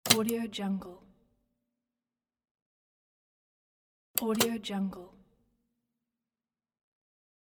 دانلود افکت صوتی ماشین تحریر 3
صدای ماشین تحریر، با ریتم منظم و کلیک‌های مشخص خود، بلافاصله تصاویری از دفاتر کار قدیمی، داستان‌های کارآگاهی، و لحظات تعیین‌کننده‌ای تاریخی را شکل می‌دهند.
Sample rate 16-Bit Stereo, 44.1 kHz
Looped No